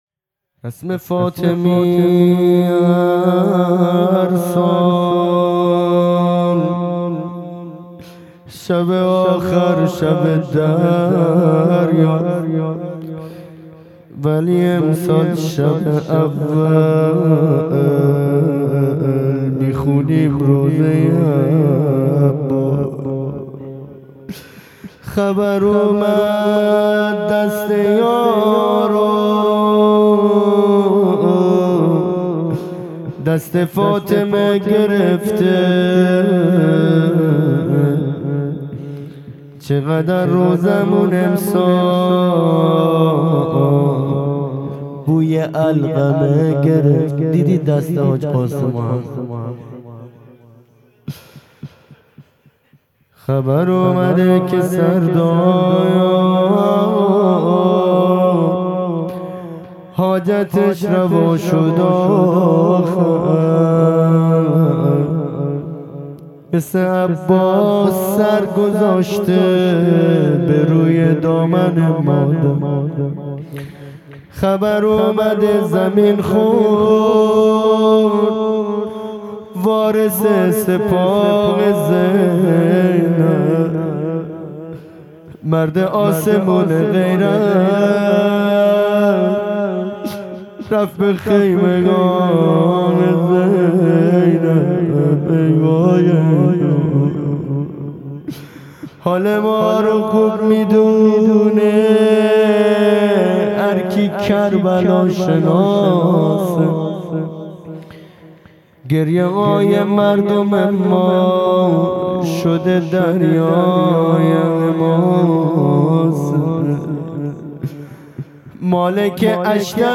مناجات پایانی | رسم فاطمیه هرسال، شب آخر شب دریاست
فاطمیه اول ‍| به‌ یاد سردار شهید حاج قاسم سلیمانی | 19 دی 1398